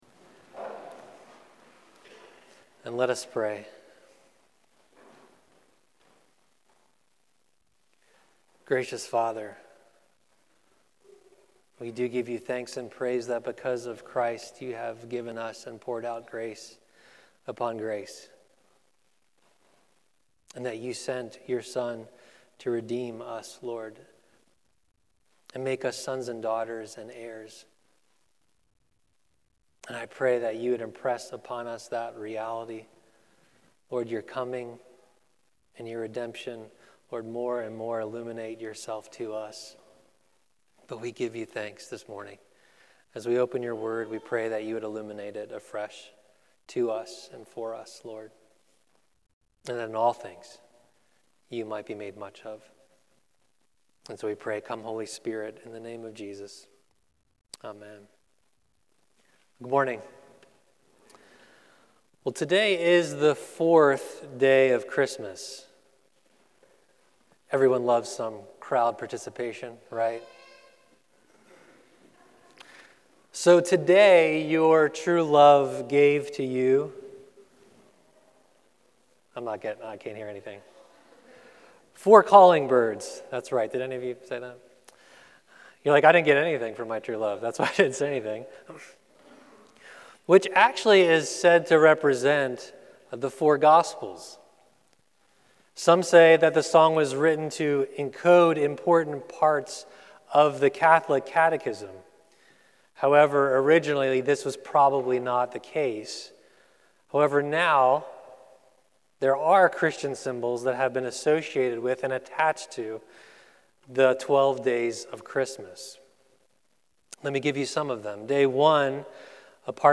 A sermon
Guest Speaker